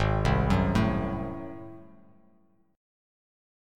Ab7b9 chord